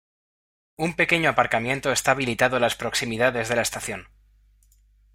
a‧par‧ca‧mien‧to
/apaɾkaˈmjento/